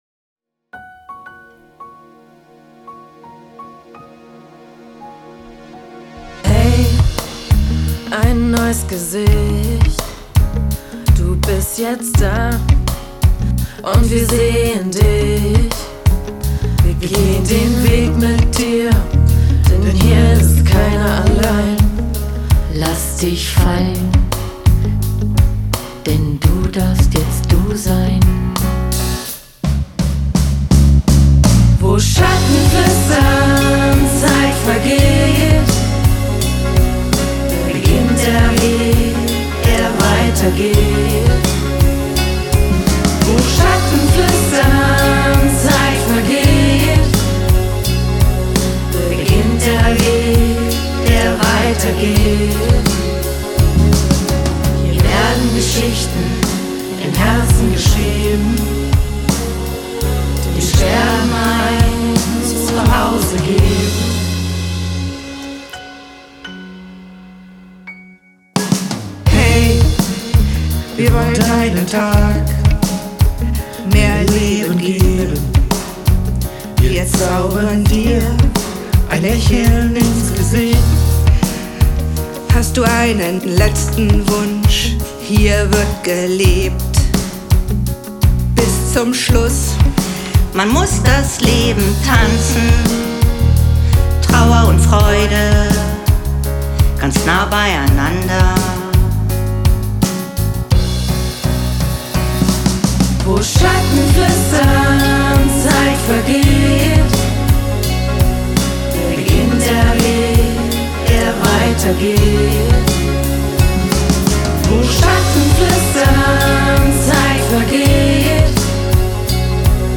Wenn Pflegekräfte singen …
Unser Weg führte uns in ein Tonstudio, wo wir in nur wenigen Stunden einen eigenen Song komponierten und aufnahmen.
Songwriting, Solisten, Chor und Trommelgruppe.
Doch bald wurde es ernst: Die Texte mussten eingesungen, die Chorstimmen abgestimmt und der Rhythmus auf den Trommeln geprobt werden.